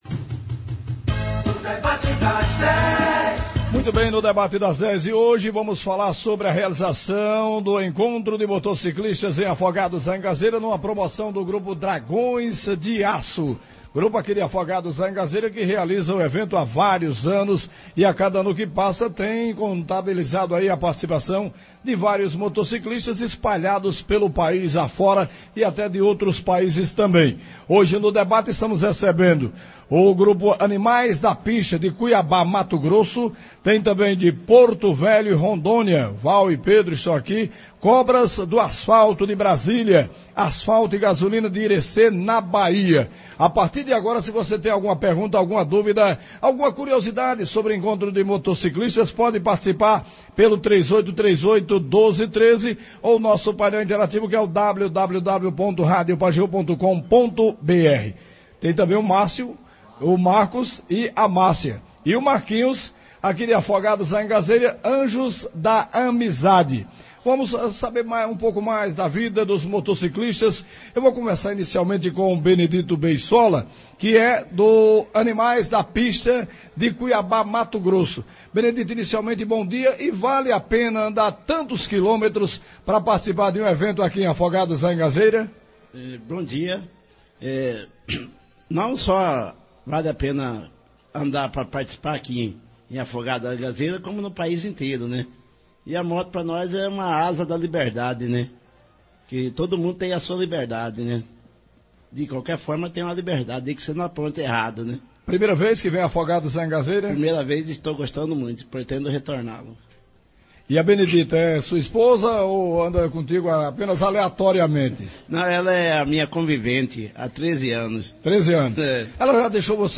Hoje nos estúdios da Rádio Pajeú, representantes de Moto Clubes de Cuiabá-MT, Porto Velho-RO, Irecê-BA, Brasília-DF e Afogados da Ingazeira-PE, falaram como é a vida de motociclista; as dificuldades, os prazeres, as amizades, os encontros e os lugares por onde passam.